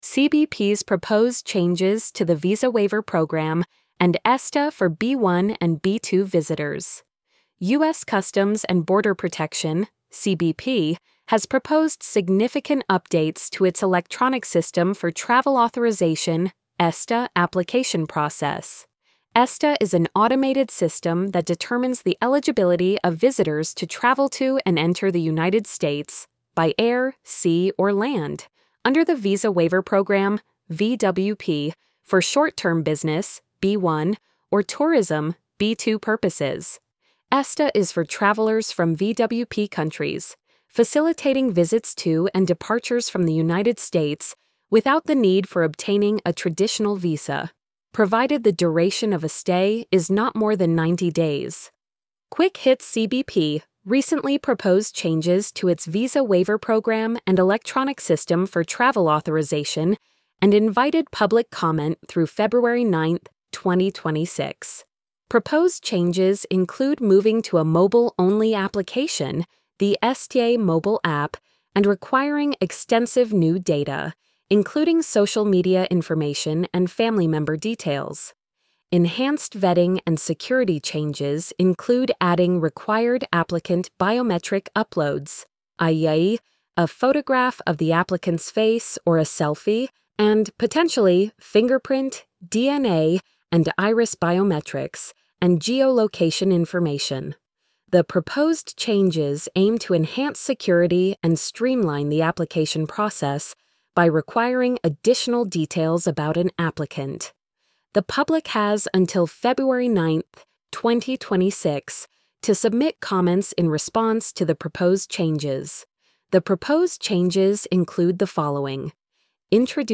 cbps-proposed-changes-to-the-visa-waiver-program-and-esta-for-b-1-and-b-2-visitors-tts-2.mp3